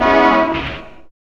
4506L CHDSYN.wav